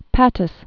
(pătəs, päts), Lagoa dos